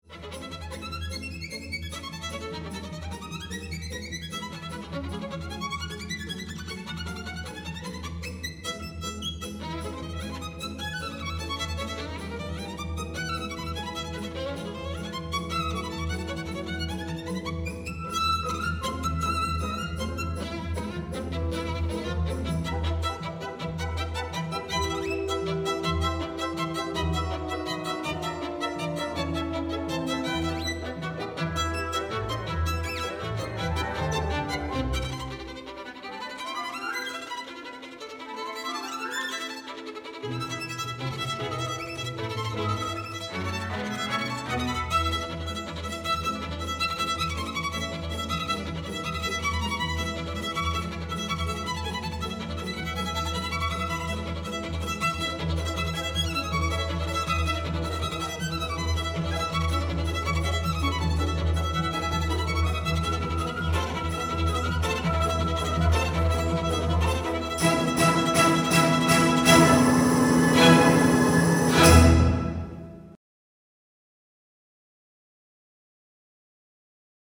巨匠ならではの加速化する曲で有名なバイオリン曲。